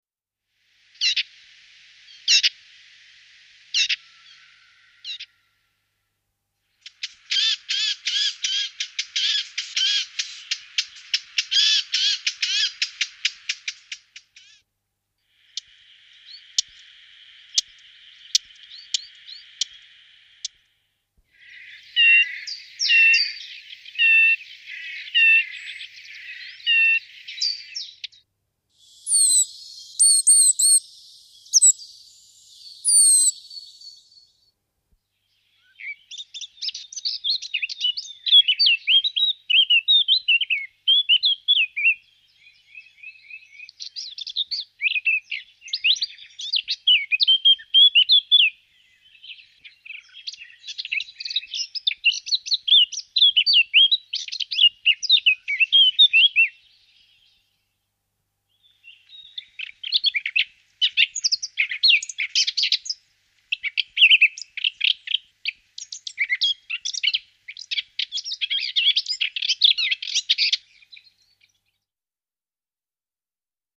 Меладычны салаўіны пошчак можна пачуць з пачатку траўня і да сярэдзіны чэрвеня.
Кожная песня салаўя складаецца з 6-12 элементаў, якія паўтараюцца.
Песня лескі-чорнагалоўкі прыгожая і даволі гучная, складаная, непаўторная, практычна без паўзаў, складаецца з серыі доўгіх пералівістых строф, у пачатку ціхіх, затым гучных флейтавых гукаў, добра запамінаецца. Яе пачатак можа гучаць як ціхае мармытанне.